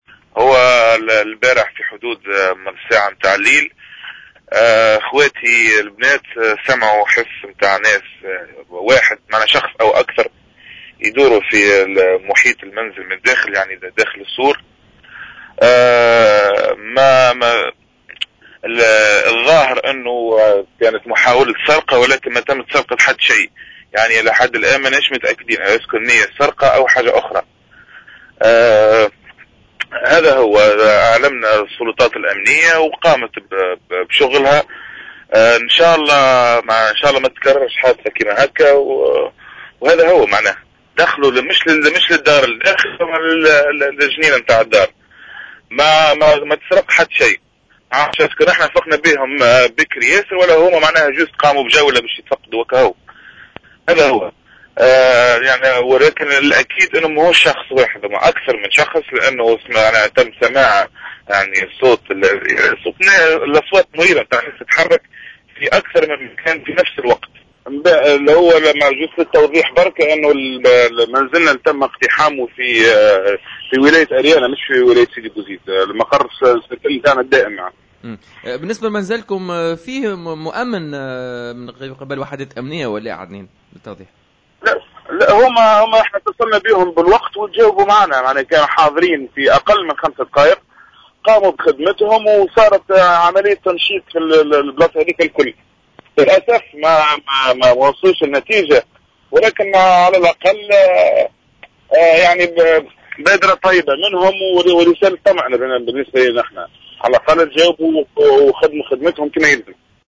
تصريح للجوهرة "اف ام"